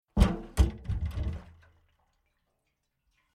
Звуки канистры